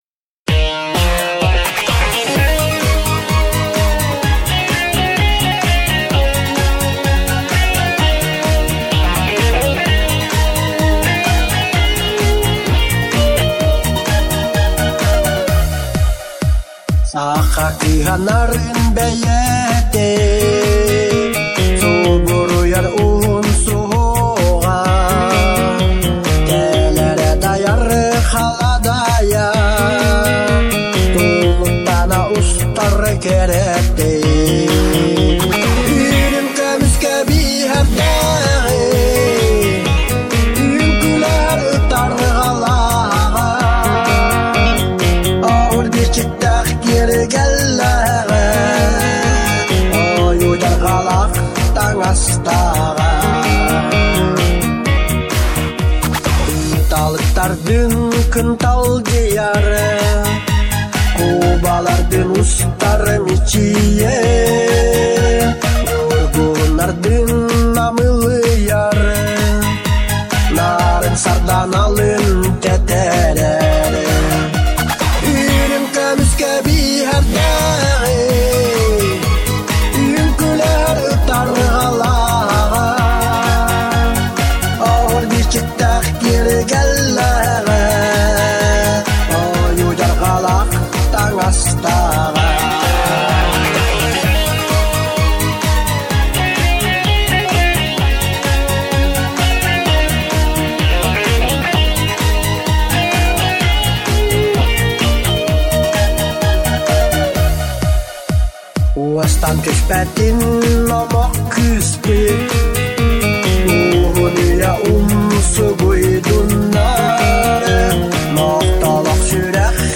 Ыллыыр автор